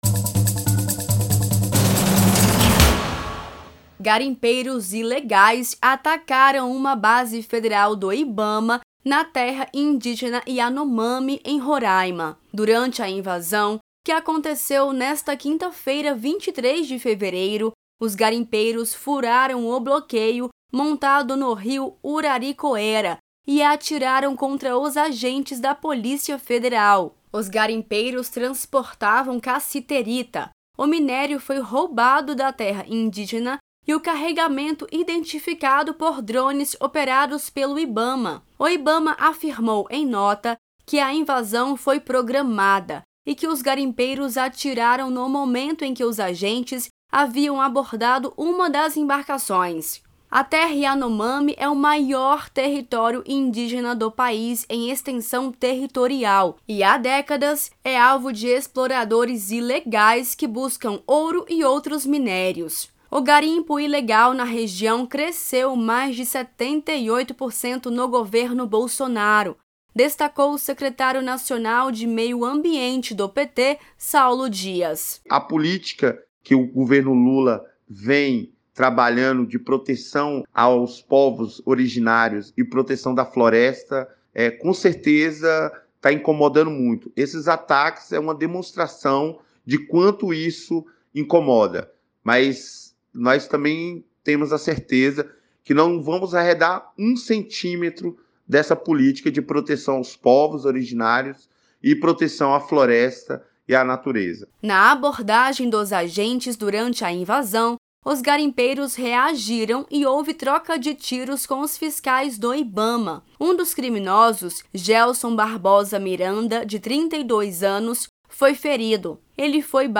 BOLETIM | Base do Ibama em Terra Yanomami é atacada por garimpeiros